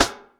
RIMSHOT  3.wav